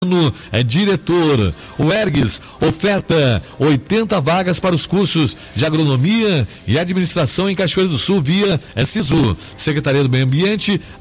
Matérias veiculadas em rádios no mês de janeiro de 2020.